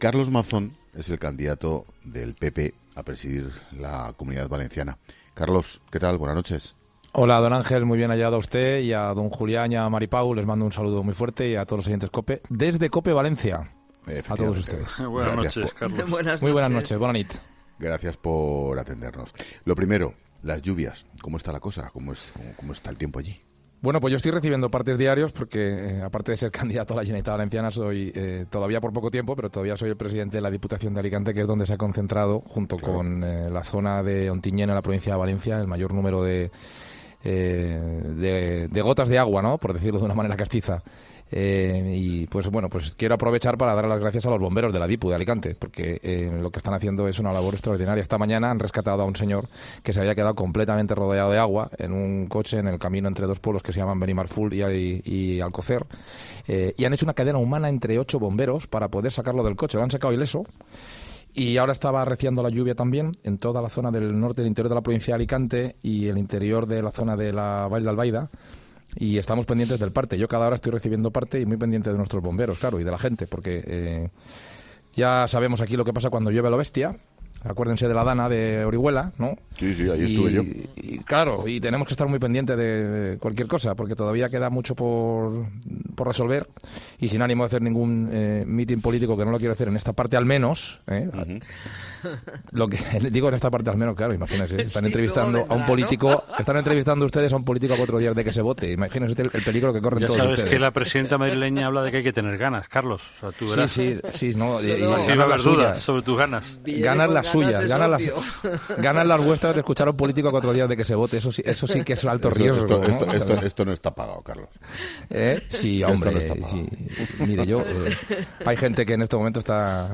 El candidato del PP a la Generalitat Valenciana analiza en La Linterna la polémica con el futbolista y las propuestas de los populares para el 28M